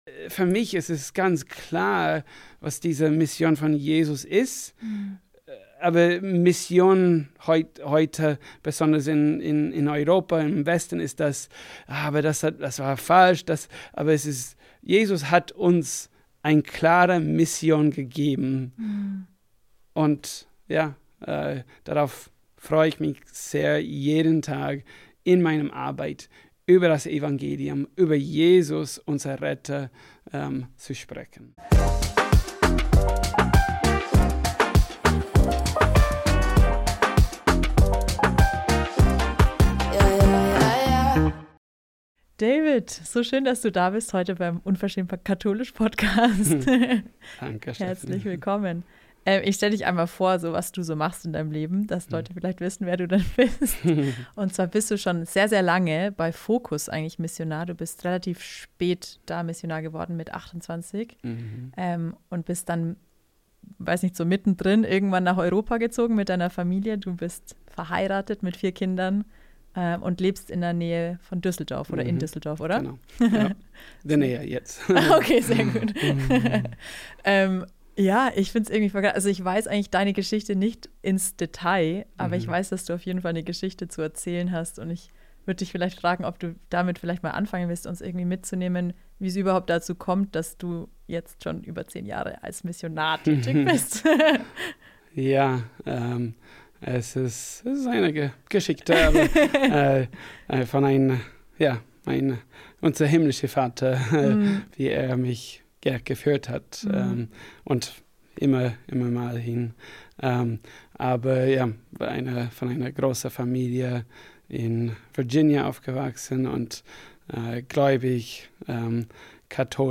Am Schluss machen wir gemeinsam eine Lectio divina live im Podcast!